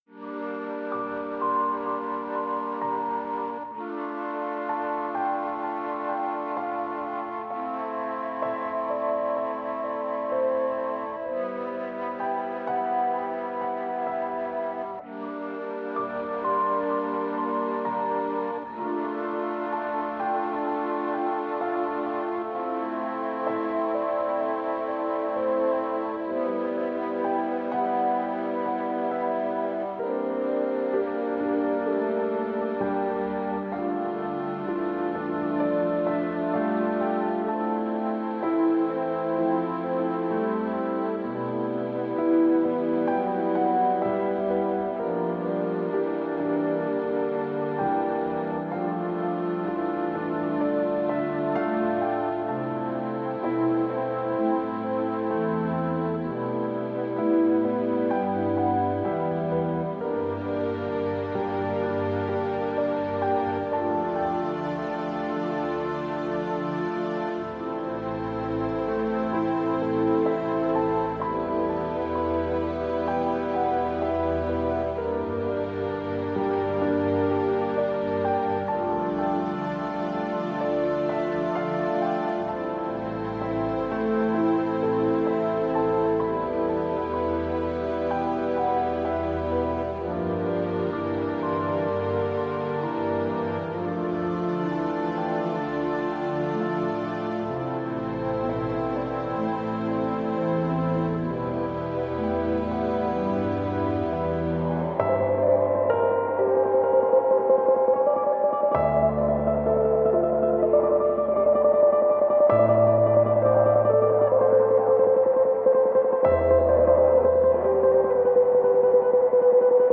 Intervista Gianni Maroccolo (Mephisto Ballad) a Puzzle